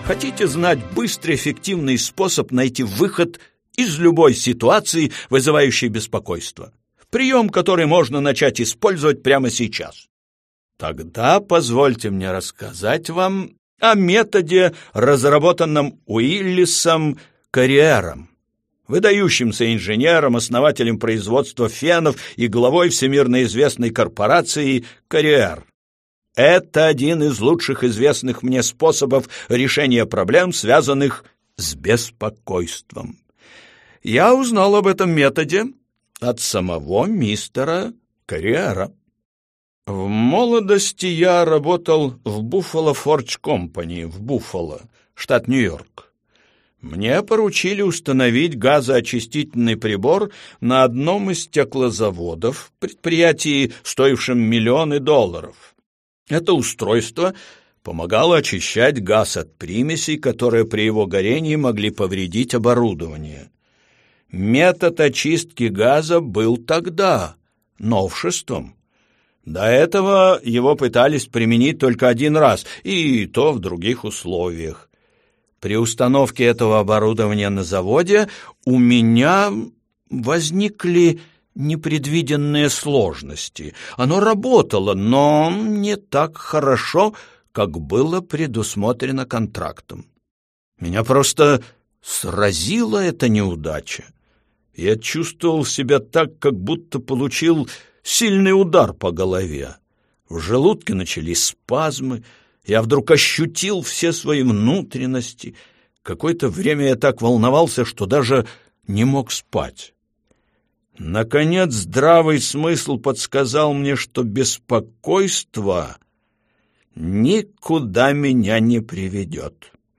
Аудиокнига Как перестать беспокоиться и начать жить | Библиотека аудиокниг